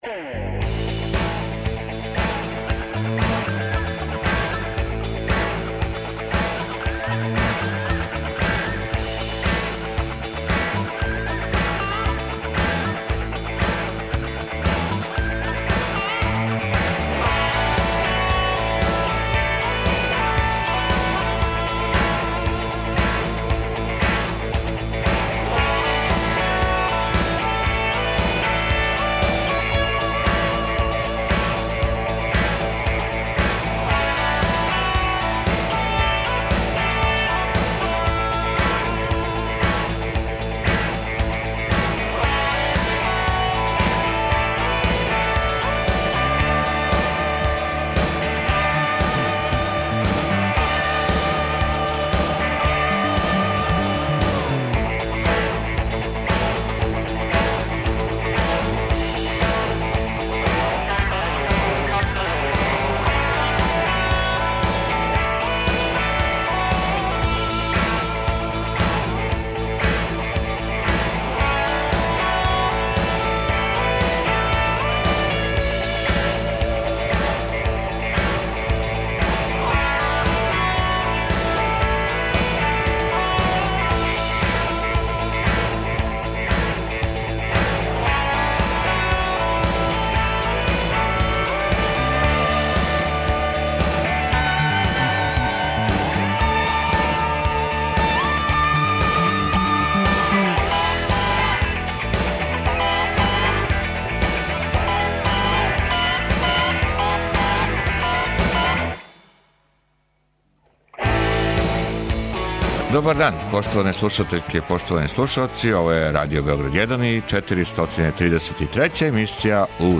И овог петка можете се укључити у програм и обратити широком аудиторијуму, наравно, само уколико знате одговор на питање недеље које гласи: Како штедети струју?